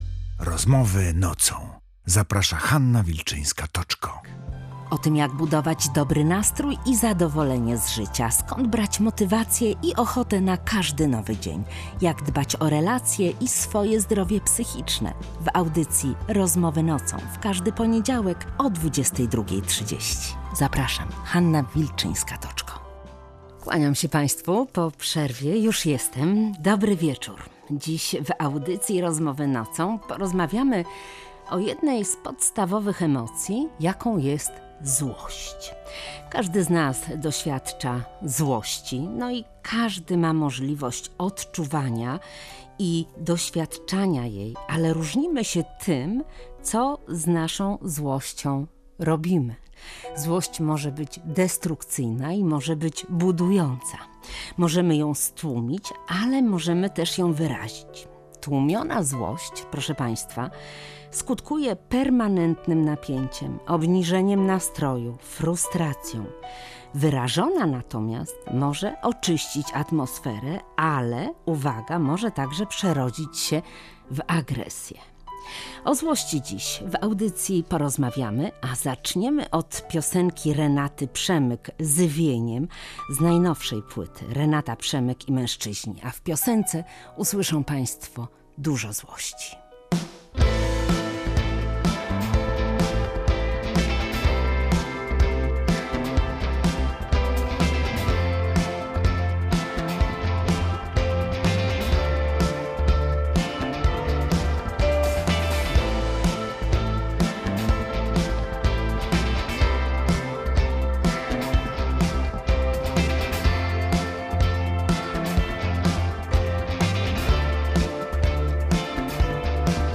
psycholożką i terapeutką.